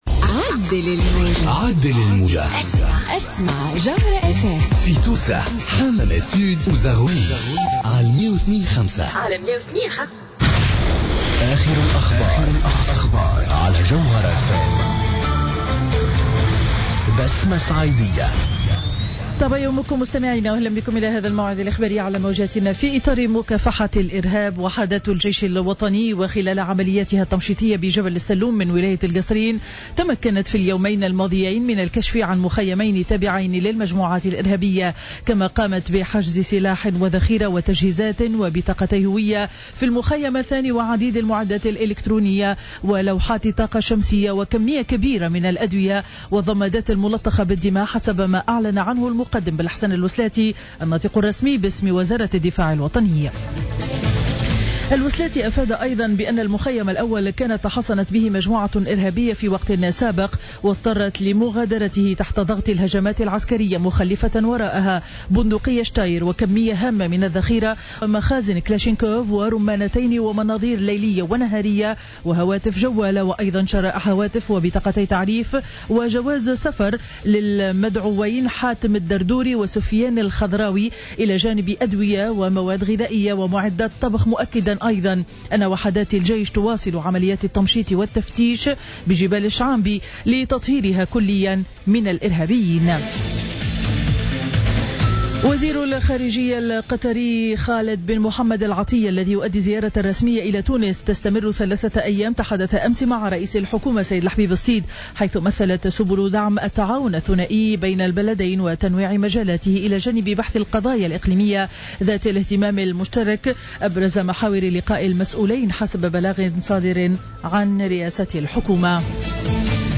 نشرة أخبار السابعة صباحا ليوم الجمعة 5 جوان 2015